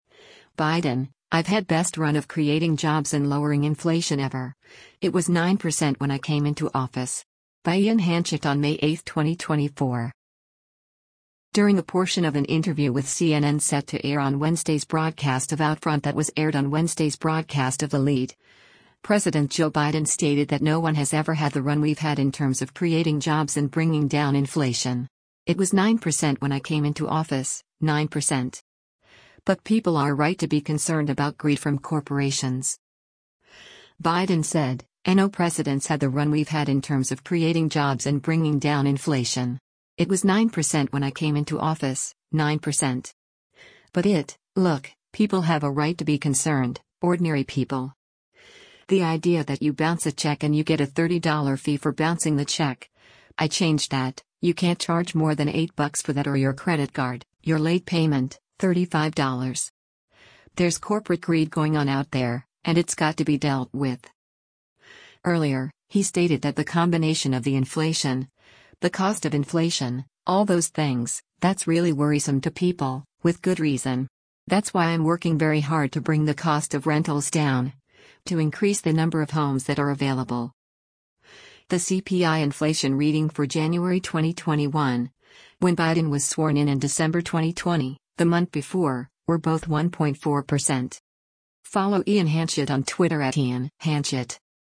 During a portion of an interview with CNN set to air on Wednesday’s broadcast of “OutFront” that was aired on Wednesday’s broadcast of “The Lead,” President Joe Biden stated that no one has ever “had the run we’ve had in terms of creating jobs and bringing down inflation. It was 9% when I came into office, 9%.” But people are right to be concerned about greed from corporations.